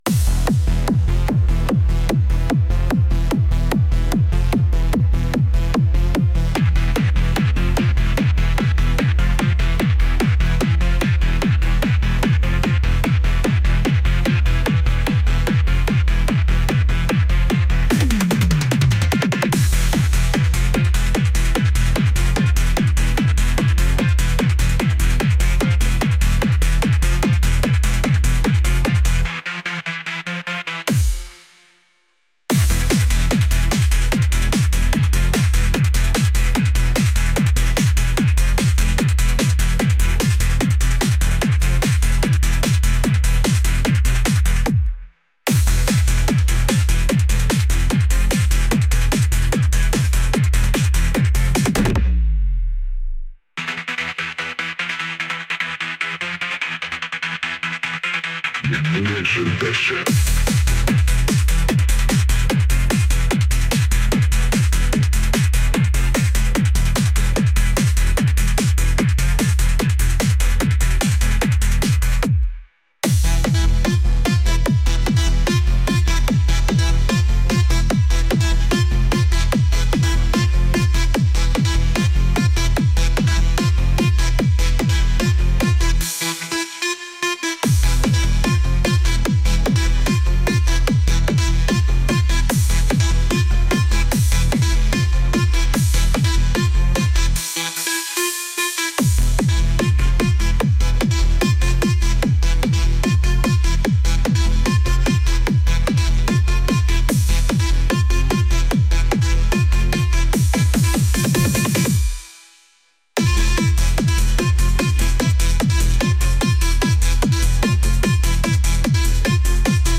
heavy